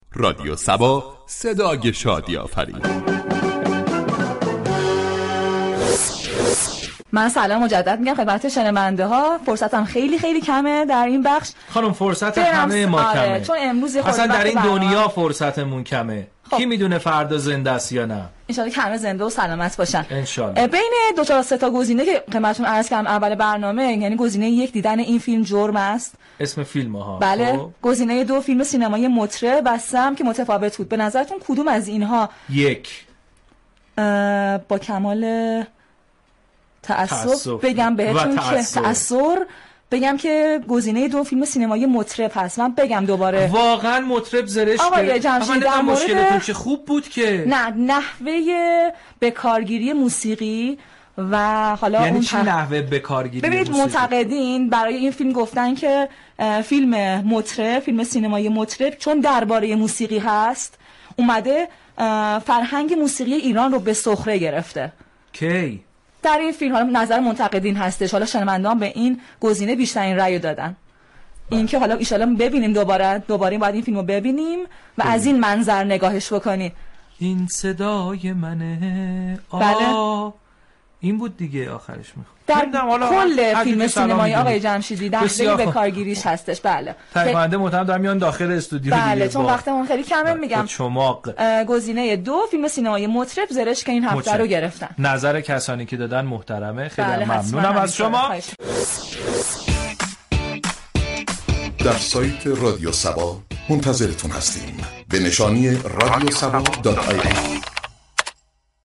این برنامه در فضایی شاد و پرانرژی تقدیم مخاطبان می شود .